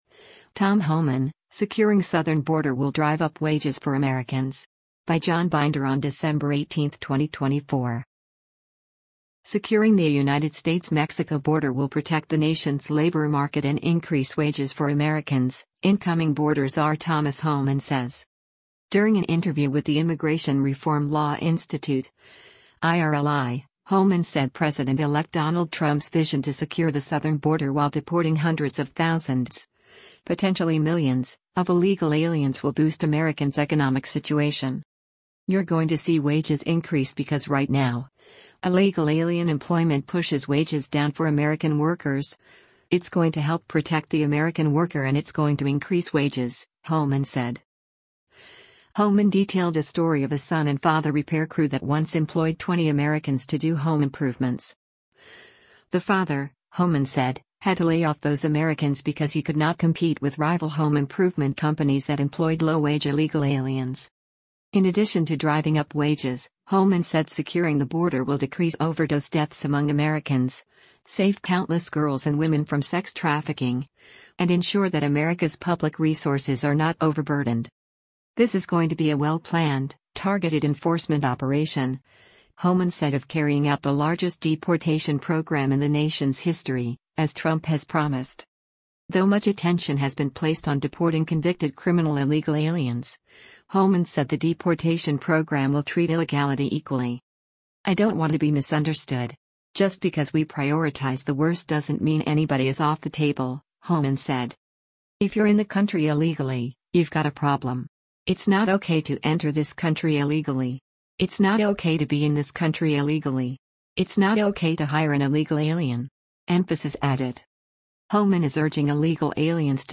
During an interview with the Immigration Reform Law Institute (IRLI), Homan said President-elect Donald Trump’s vision to secure the southern border while deporting hundreds of thousands, potentially millions, of illegal aliens will boost Americans’ economic situation.